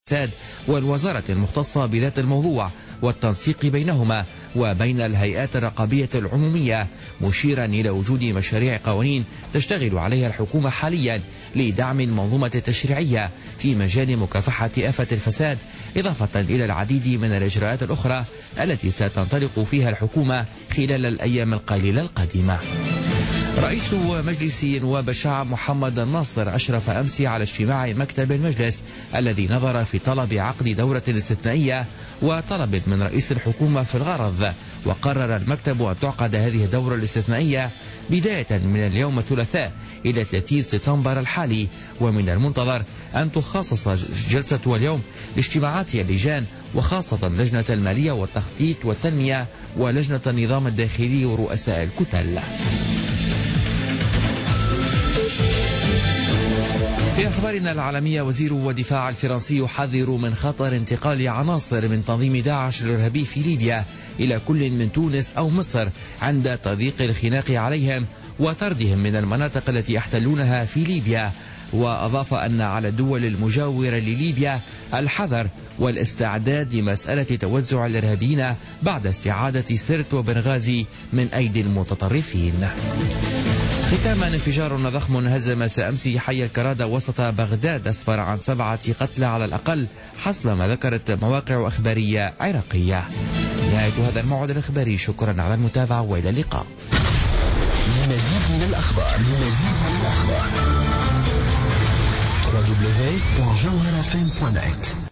Journal Info 00h00 du mardi 6 septembre 2016